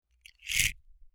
Part_Assembly_42.wav